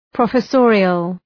Προφορά
{,prɒfə’sɔ:rıəl}